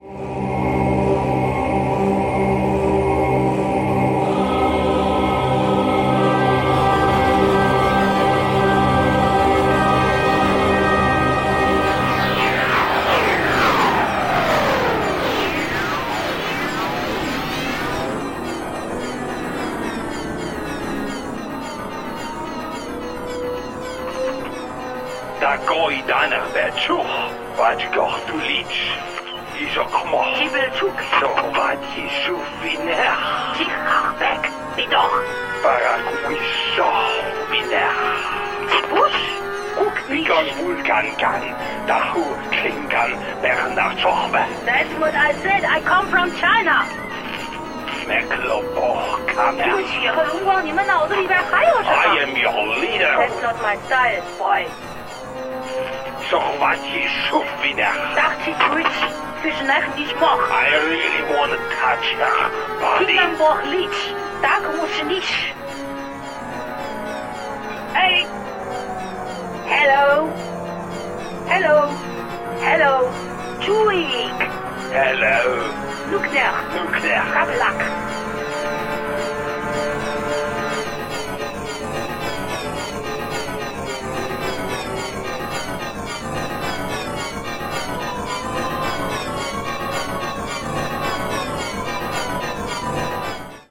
It is orchestral music produced with electronic devices
The vocals are Klingon and Chinese."